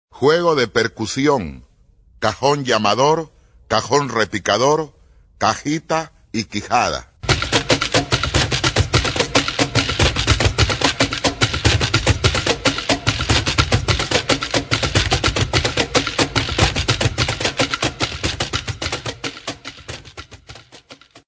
Sonidos de instrumentos Afroperuanos
"Juego de percusión" : cajón llamador, cajón repicador, cajita y quijada